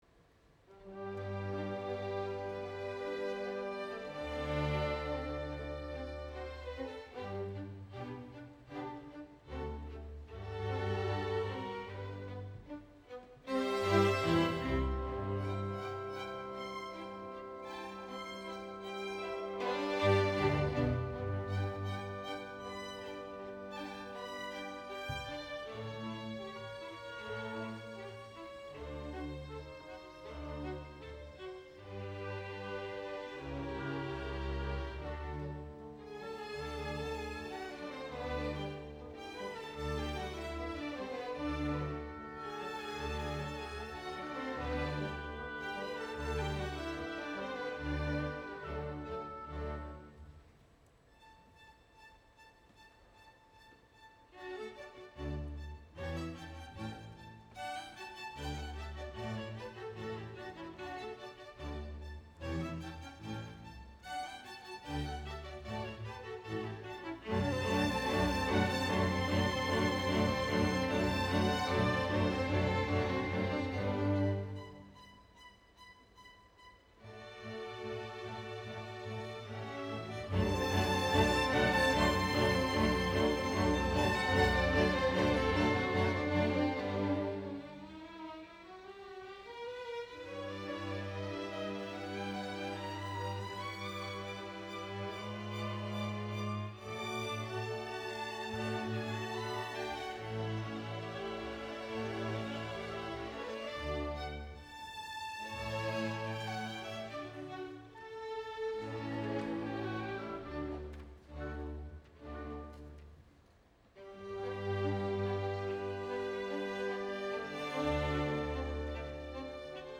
Вот записал симф оркестр на три микрофона по системе "Radiator Array" прошу поругать как следует Какие фазовые проблемы кто слышит, и ошибки в стерео образе?